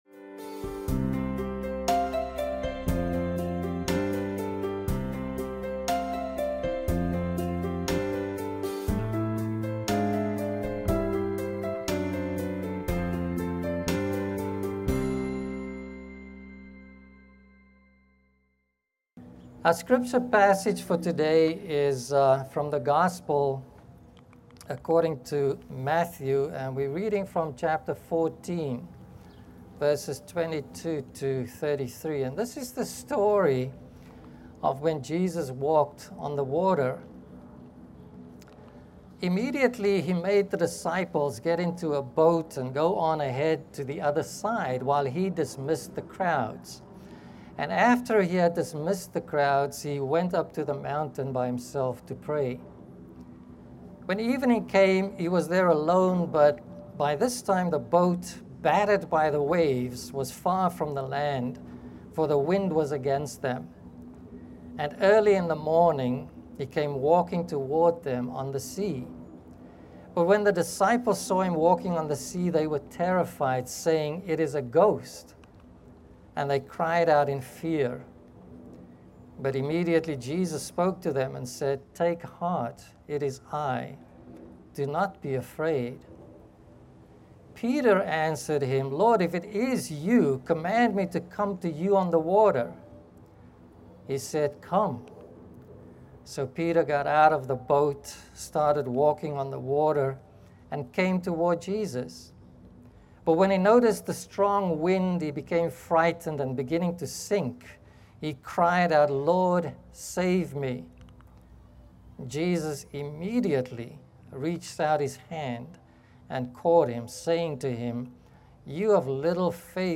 Sermons | Community Church of Douglaston